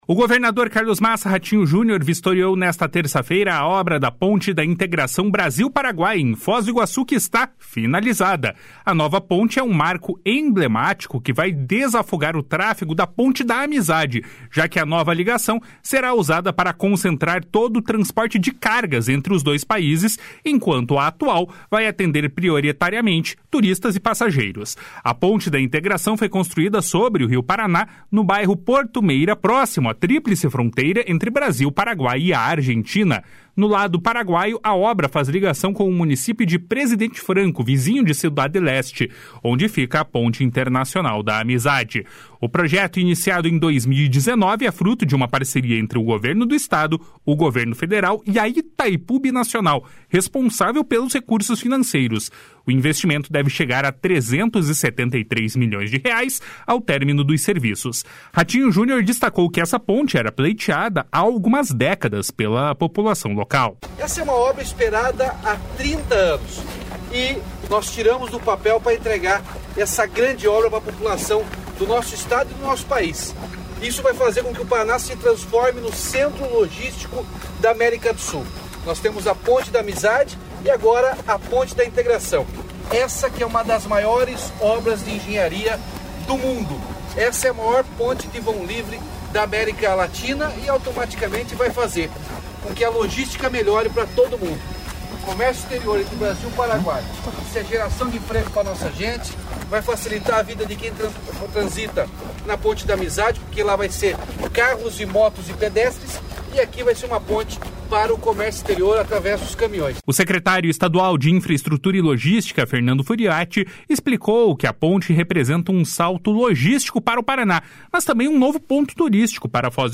Ratinho Junior destacou que essa ponte era pleiteada há algumas décadas pela população local. // SONORA RATINHO JUNIOR //
O secretário estadual de Infraestrutura e Logística, Fernando Furiatti, explicou que ela representa um salto logístico para o Paraná, mas também um novo ponto turístico para Foz do Iguaçu. // SONORA FERNANDO FURIATTI //